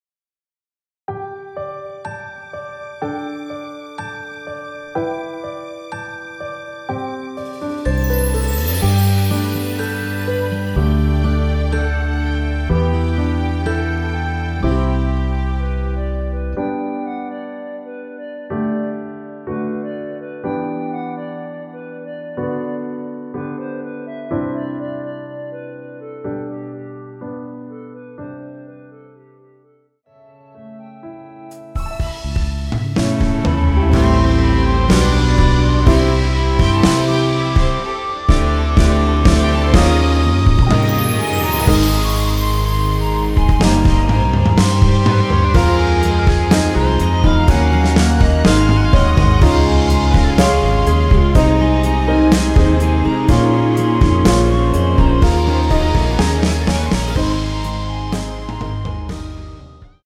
원키에서(+2)올린 멜로디 포함된 MR입니다.
앞부분30초, 뒷부분30초씩 편집해서 올려 드리고 있습니다.
중간에 음이 끈어지고 다시 나오는 이유는